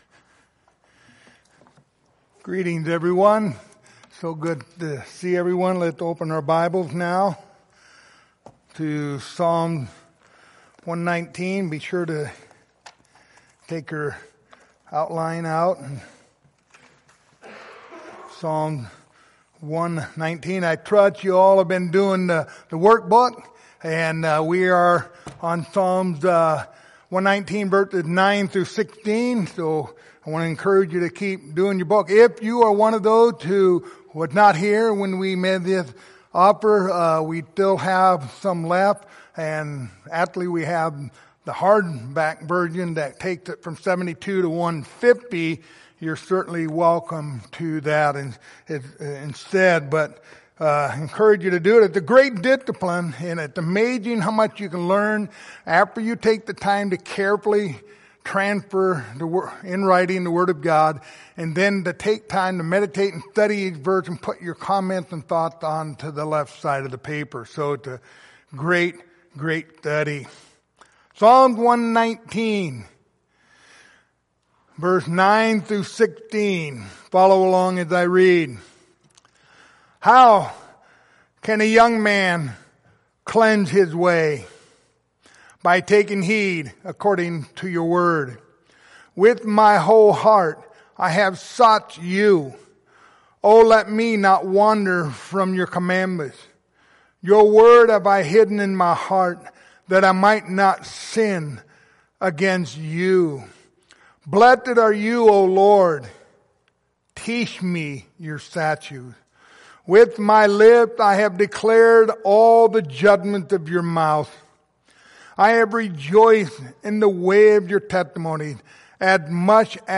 Psalm 119 Passage: Psalms 119:9-16 Service Type: Sunday Morning Topics